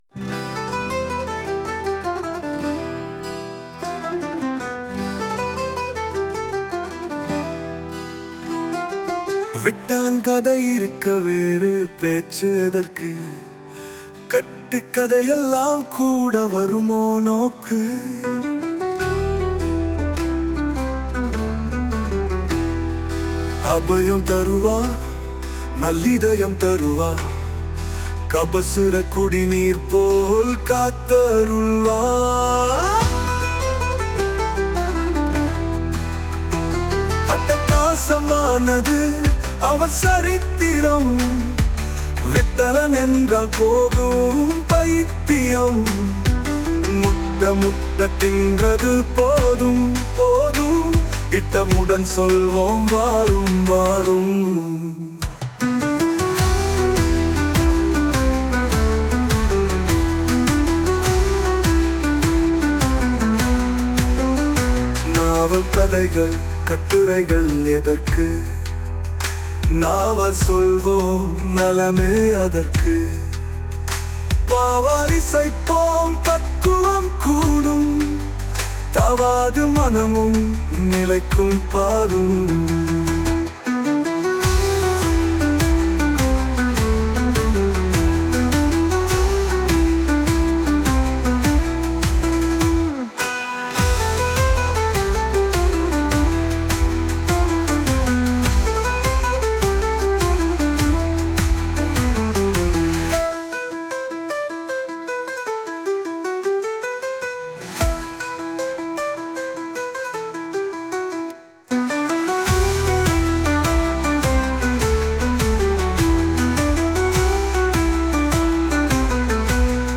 Posted in தமிழ் அபங்கங்கள், பாடல்கள்
Tamil-Abhangam-2.mp3